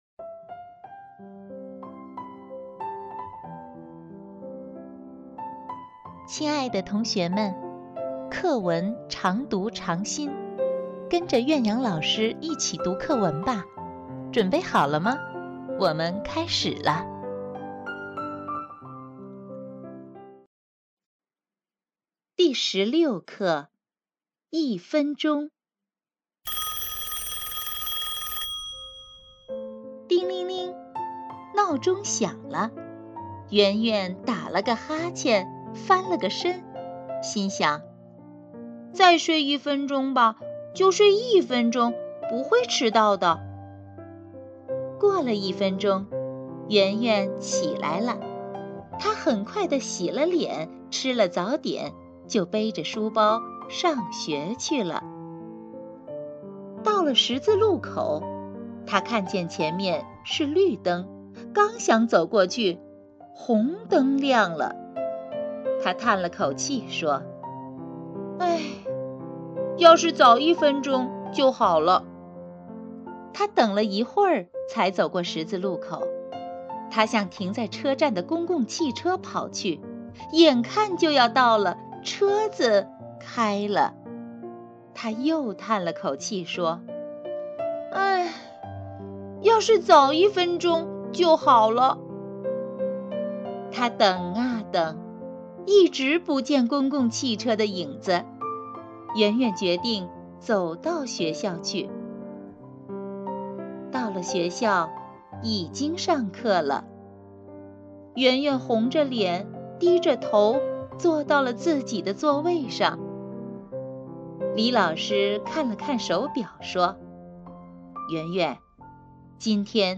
课文朗读